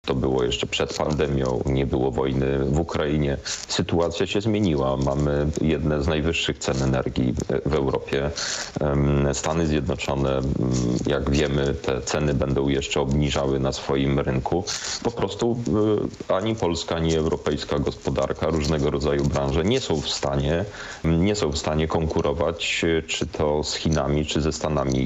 Europoseł Krzysztof Hetman widzi potrzebę zmian w Europejskim Zielonym Ładzie. Polityk Polskiego Stronnictwa Ludowego powiedział w Programie I Polskiego Radia, że obecnie ani polska ani europejska gospodarka nie są w stanie konkurować ze światowymi mocarstwami - Chinami czy Stanami Zjednoczonymi.